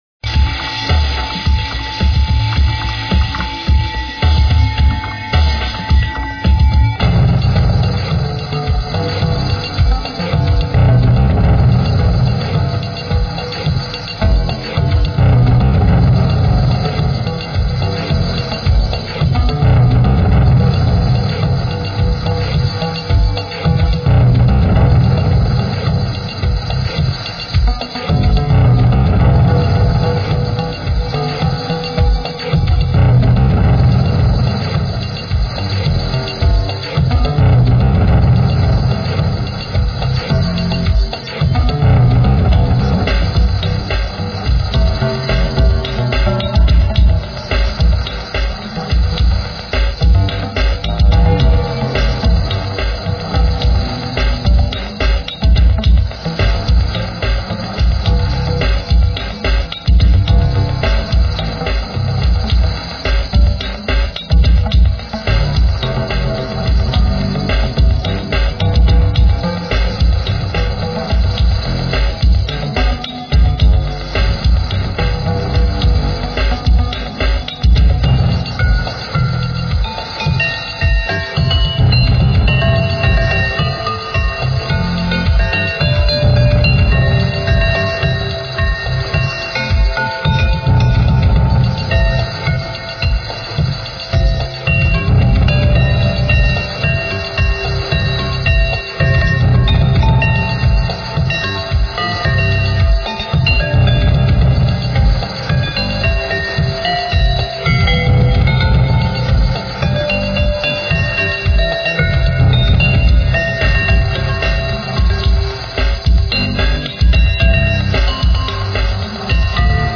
Royalty Free Music for use in any type of
Heavy backing beat with some bell like percussion
and deep growling basses.